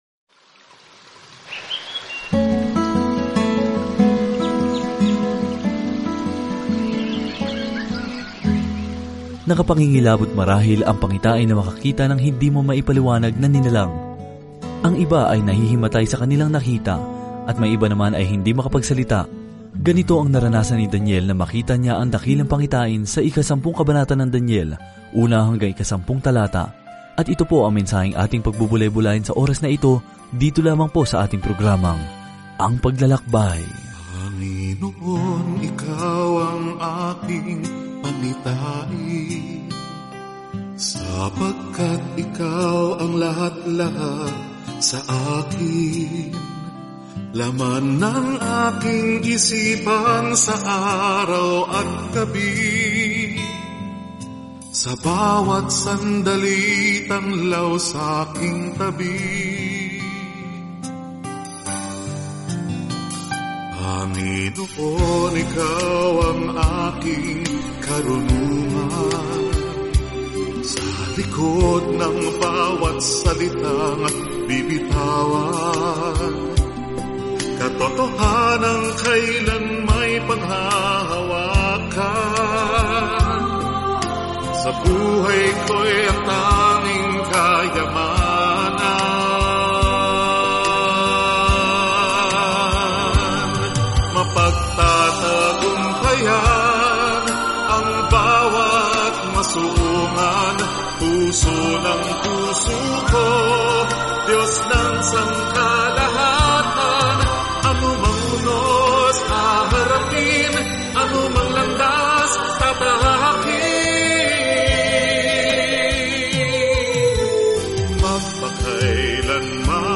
Banal na Kasulatan Daniel 10:1-10 Araw 24 Umpisahan ang Gabay na Ito Araw 26 Tungkol sa Gabay na ito Ang aklat ni Daniel ay parehong talambuhay ng isang taong naniwala sa Diyos at isang makahulang pangitain kung sino ang mamamahala sa daigdig. Araw-araw na paglalakbay kay Daniel habang nakikinig ka sa audio study at nagbabasa ng mga piling talata mula sa salita ng Diyos.